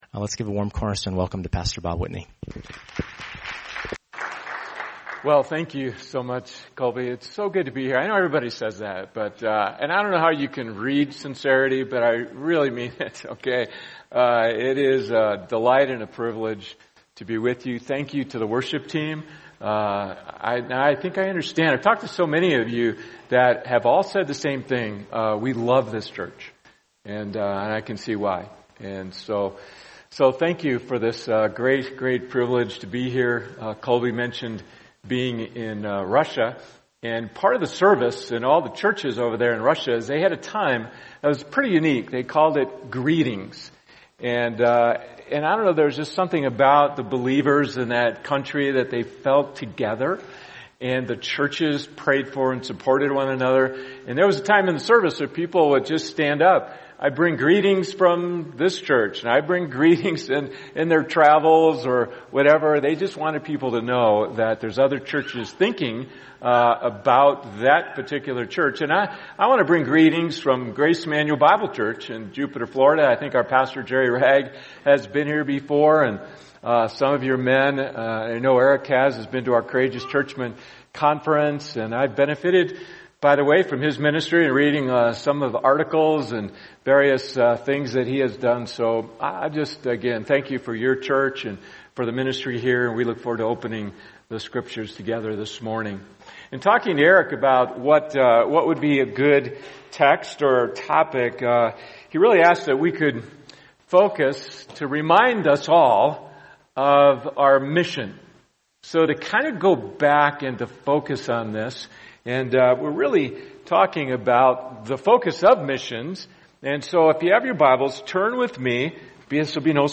[sermon] Mathew 28:18-20 The Great Commission | Cornerstone Church - Jackson Hole